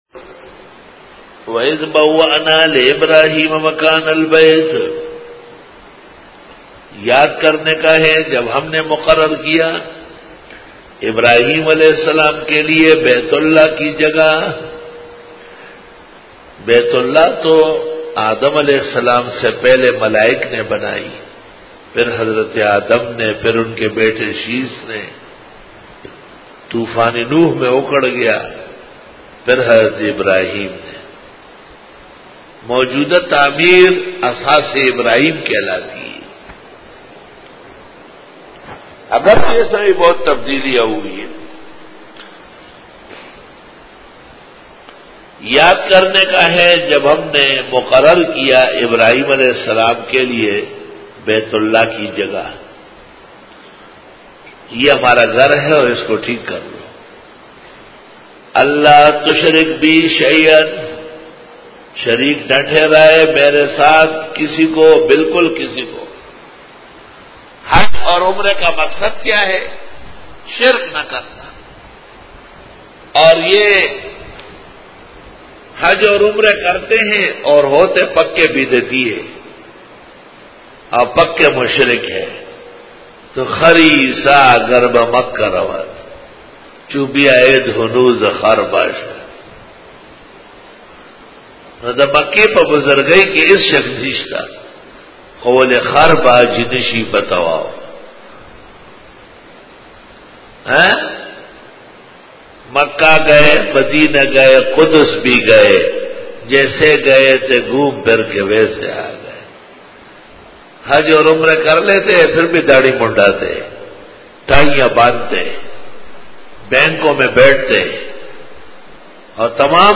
Dora-e-Tafseer 2012